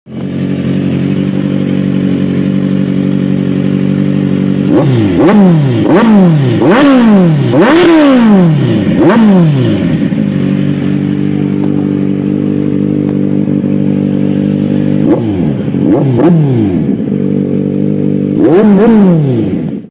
Singer: pô xe pkl kêu, nẹt bô
Genre: Âm thanh tiếng động